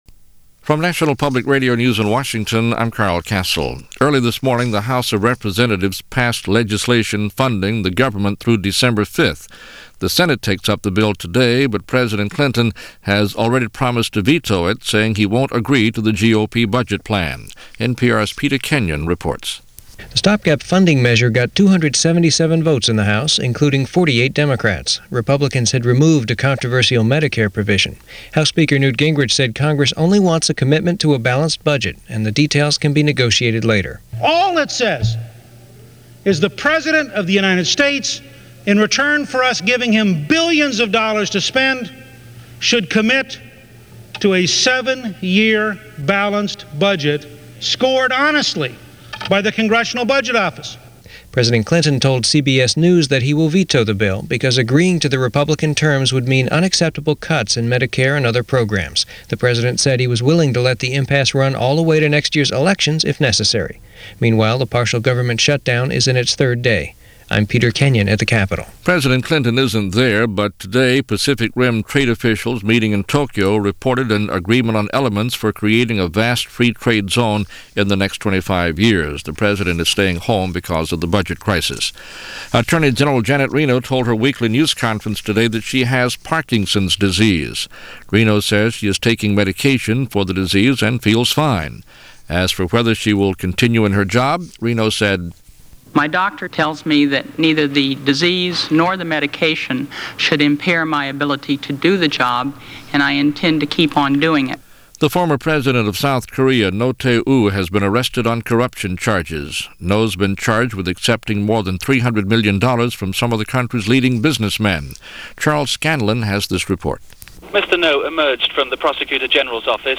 All that, and a lot more for an otherwise uneventful (yet eventful in the long-run) November 16th in 1995, as reported by National Public Radio.